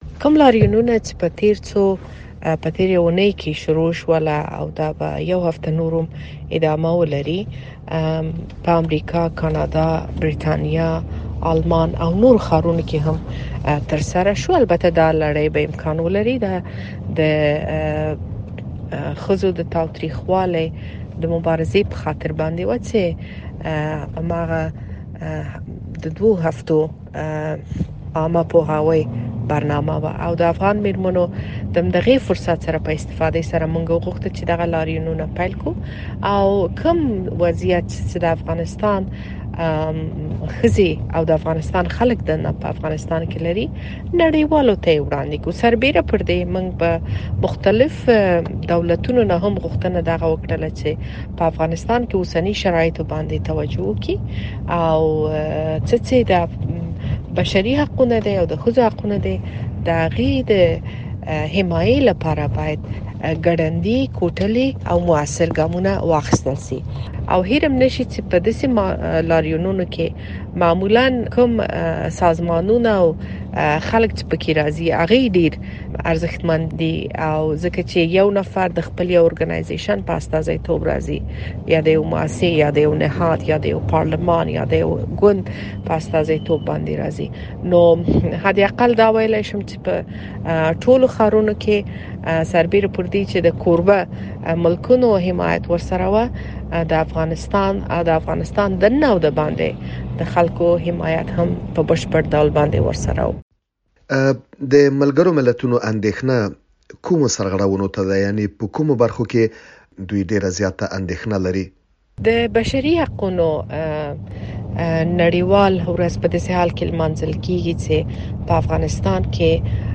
د شکريې بارکزۍ مرکه